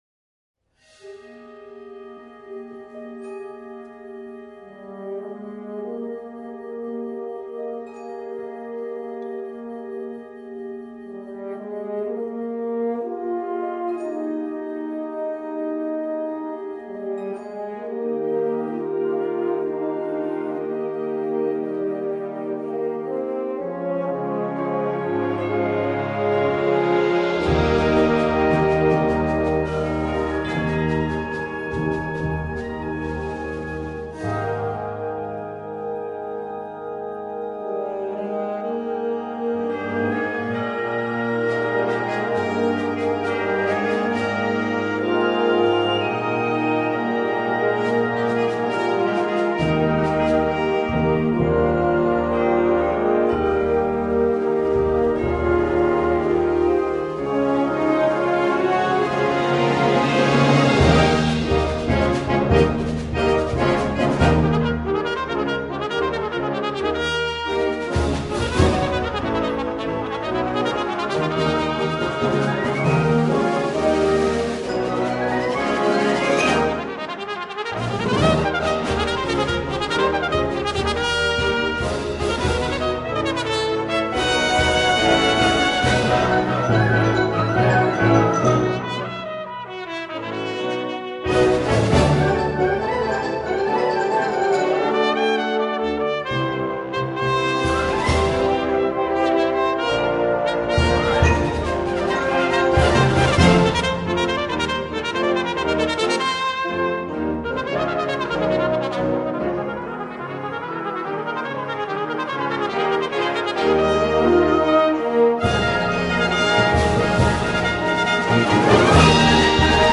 Instrumental Concert Band Section/Solo Features
Concert Band